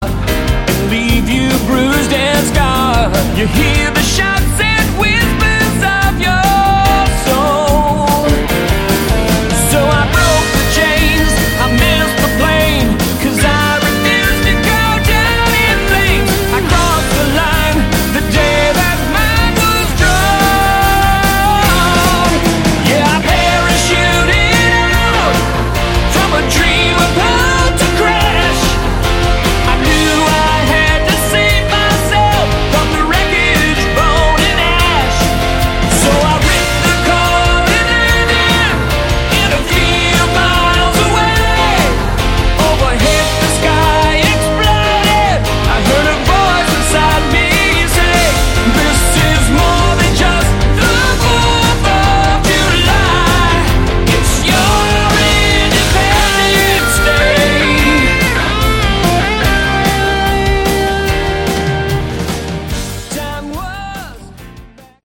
Category: AOR
lead vocals
guitar, keyboards
bass
drums